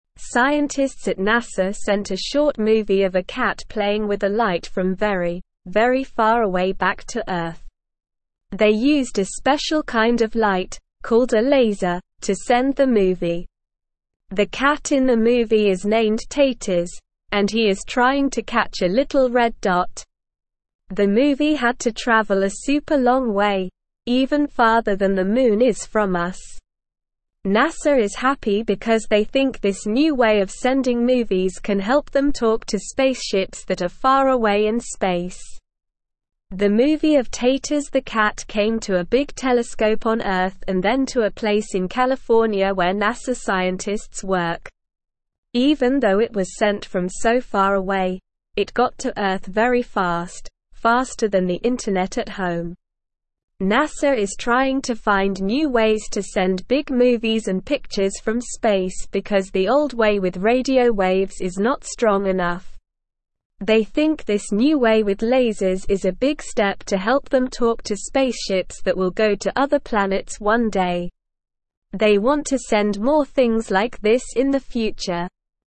Slow
English-Newsroom-Beginner-SLOW-Reading-NASA-Sends-Movie-of-Cat-Playing-with-Light-from-Space.mp3